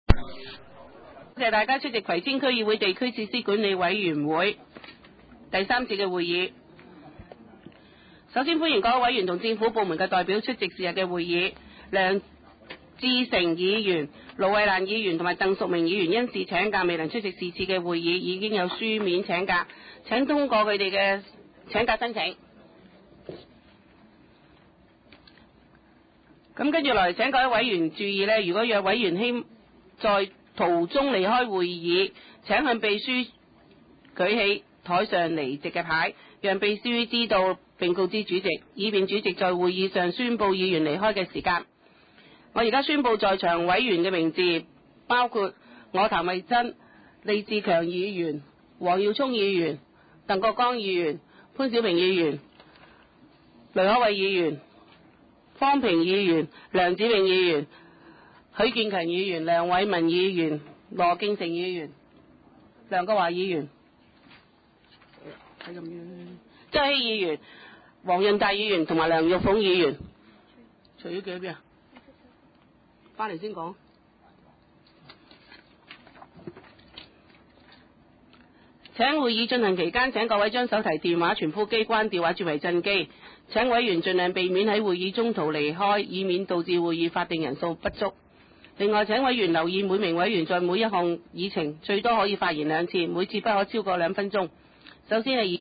葵青民政事務處會議室
開會詞